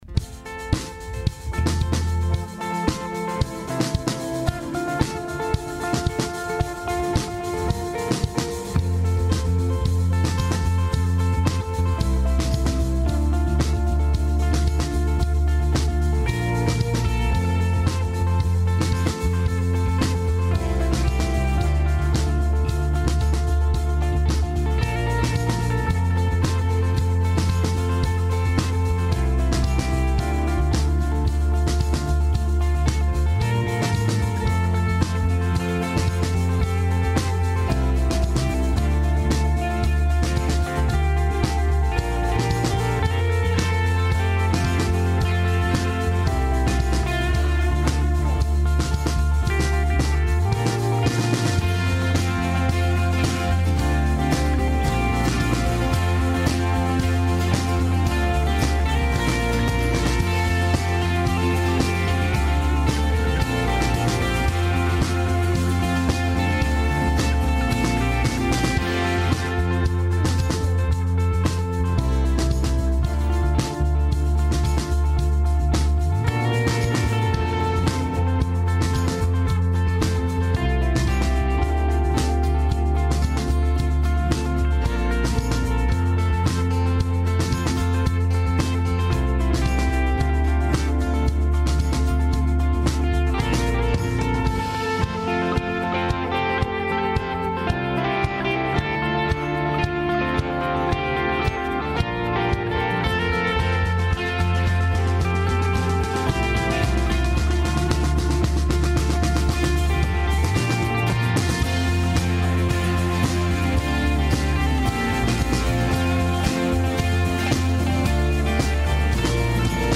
Westgate Chapel Sermons Let's Talk About It - Negative Self-Image Feb 06 2022 | 01:19:30 1x 00:00 / 01:19:30 Subscribe Share Apple Podcasts Overcast RSS Feed Share Link Embed